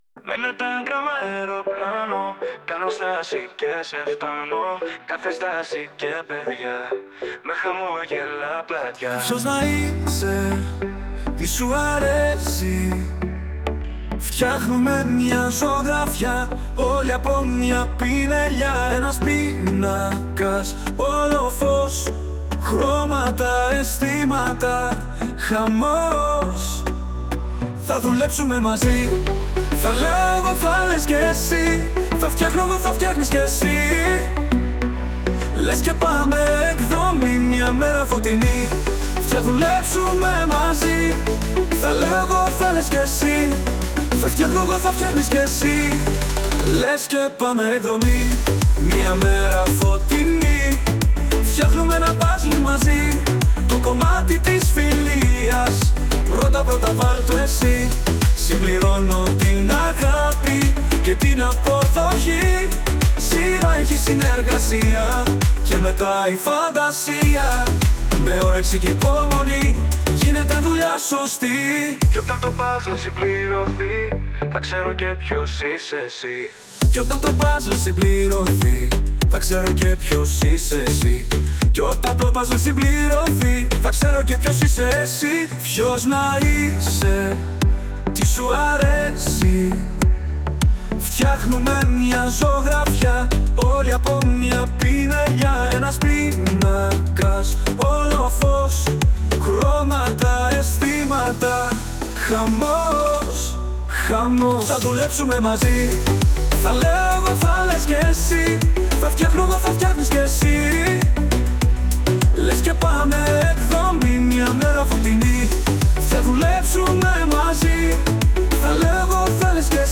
Το σχολείο μας συμμετείχε στην πανελλήνια εκδήλωση που έγινε σε διαδικτυακό επίπεδο, με μια δημιουργία των παιδιών της Β’ τάξης: ένα χαρούμενο τραγούδι, τους στίχους του οποίου έγραψαν τα παιδιά, και μετά μελοποιήθηκε με τη βοήθεια της τεχνητής νοημοσύνης!
ενώ για τη σύνθεση της μουσικής και την εκτέλεση του τραγουδιού χρησιμοποιήθηκε η εφαρμογή Suno AI Music.